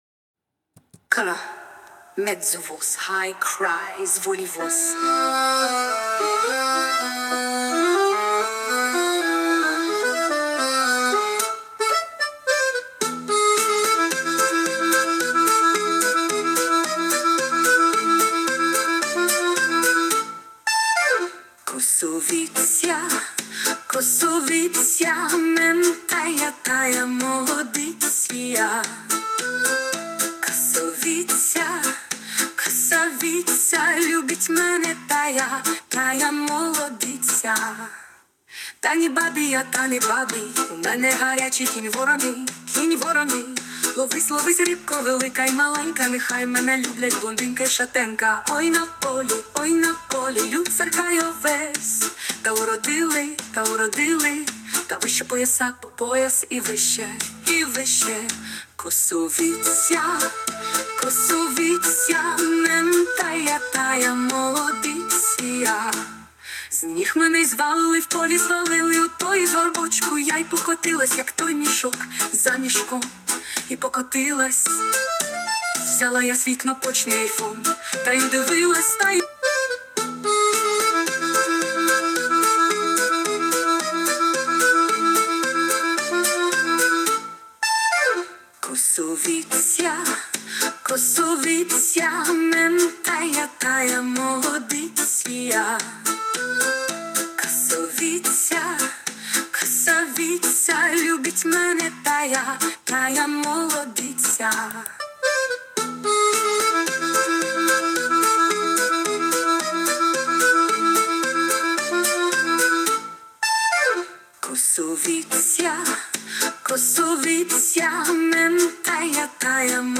Якби не жінка це співала було б краще... hi 12
так жінки кажуть, я цю пісню сам співаю тохи в іншому стилі та мені жіночий голос заходить biggrin